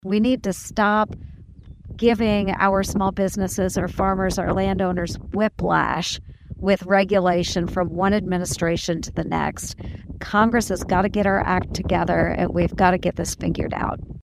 Ernst made her comments Friday in Willey.